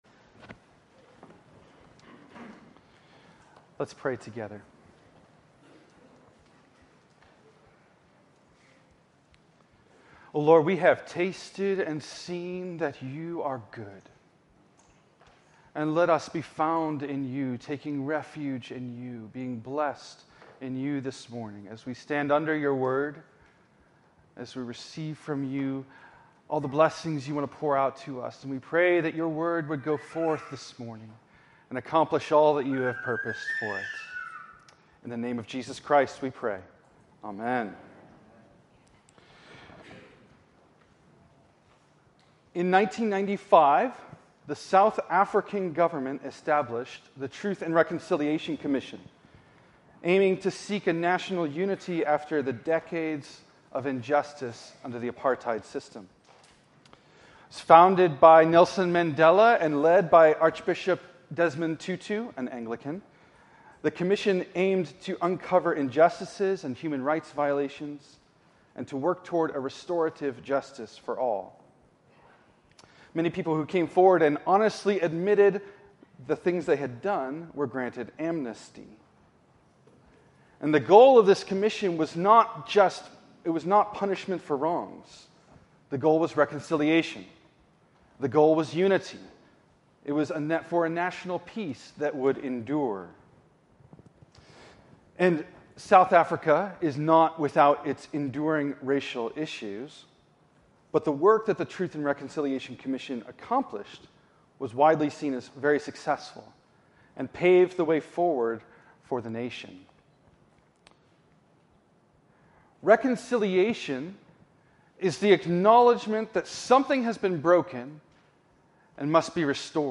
Continuing our Lenten Way of the Cross sermon series